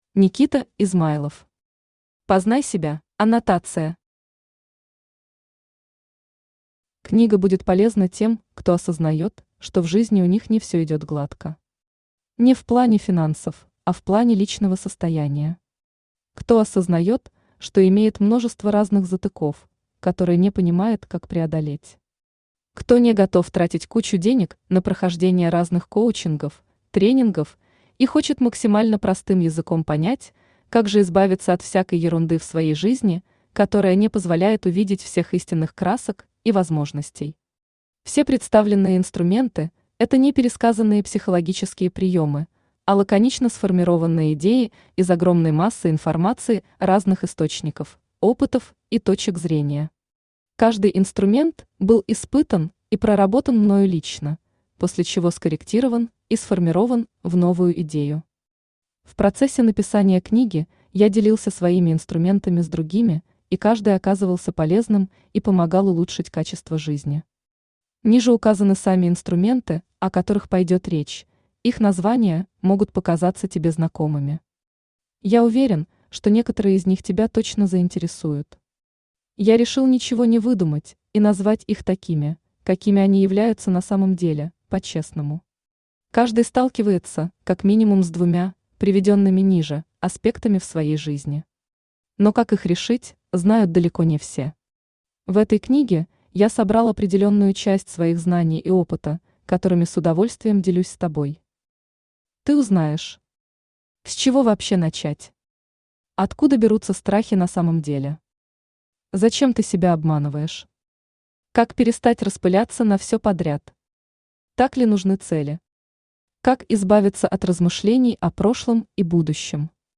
Аудиокнига Познай себя | Библиотека аудиокниг
Aудиокнига Познай себя Автор Никита Измайлов Читает аудиокнигу Авточтец ЛитРес.